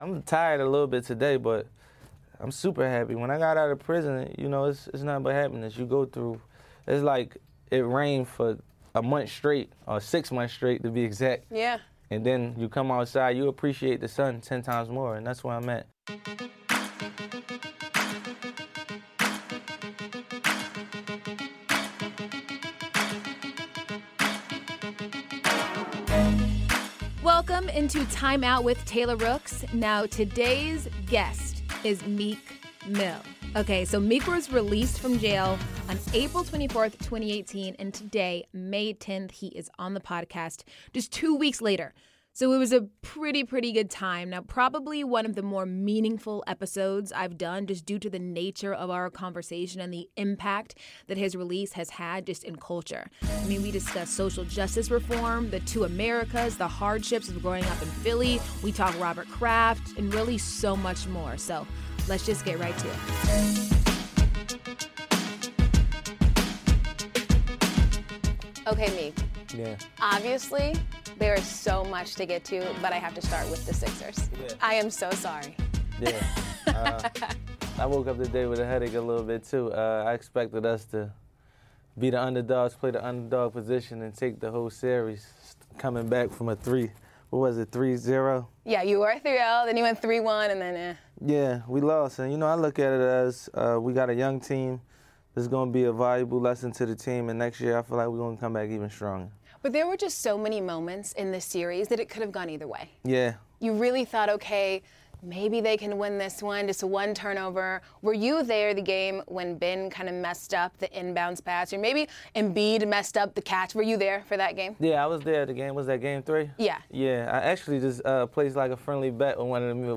In the latest episode of Timeout with Taylor Rooks, rapper Meek Mill stops by the SNY studios to discuss his release from prison, what two Americas look like, the reality of being on probation, his biggest responsibility, and of course, the Sixers elimination from the NBA Playoffs.